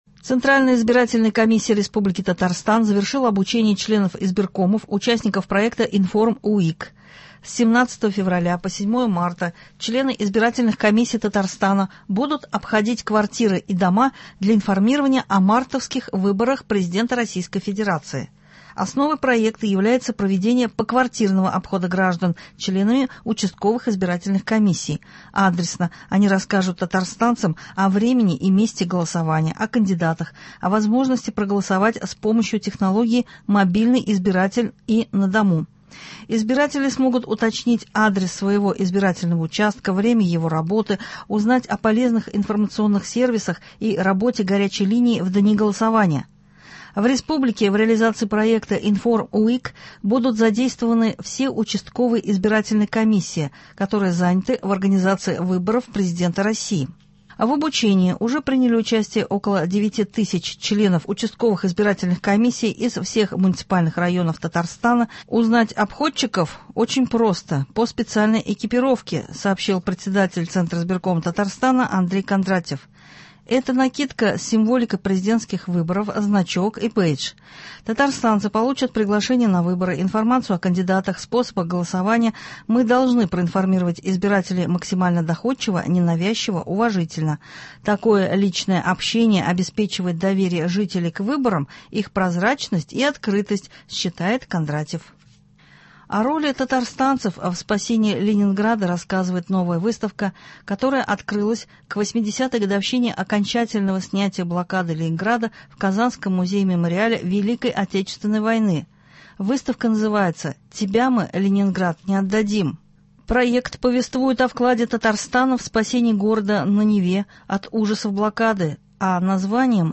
Новости (29.01.24)